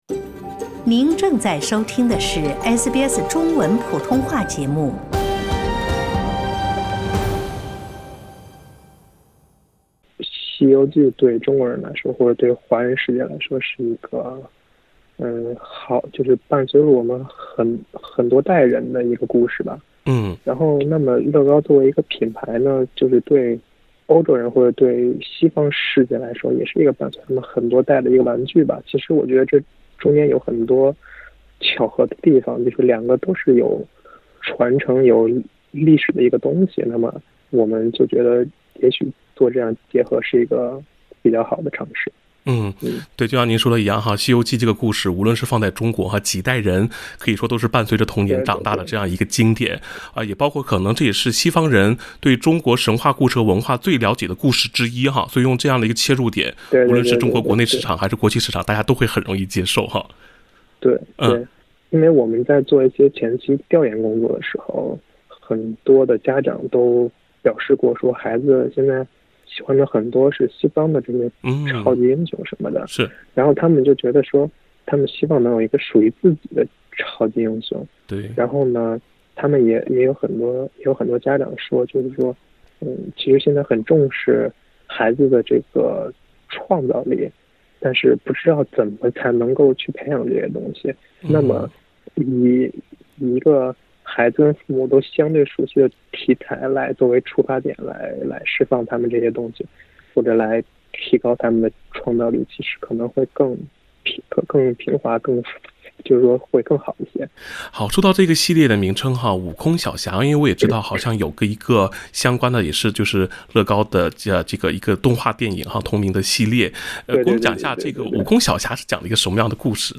当国际玩具品牌遇到中国传统神话故事后，激烈碰撞产生了新的灵感。(点击封面图片，收听采访录音）